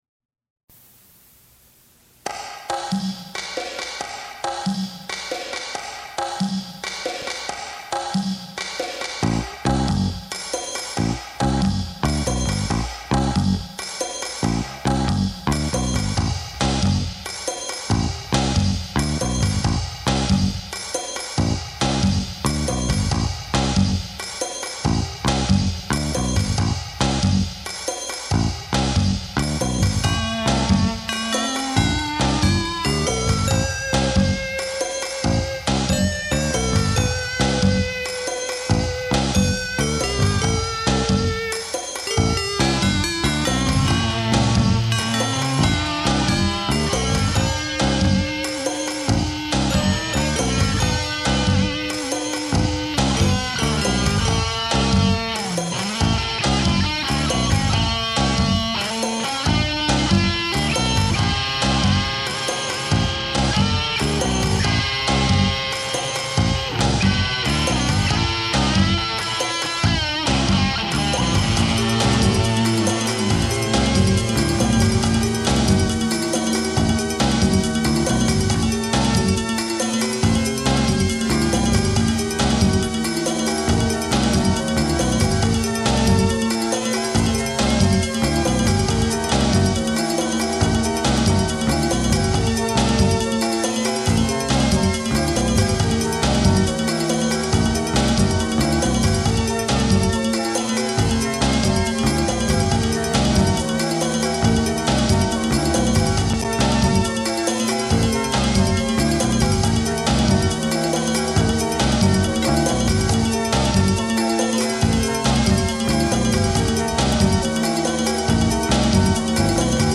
During that weekend the group recorded the ten songs on a cassette tape deck.
instrumental songs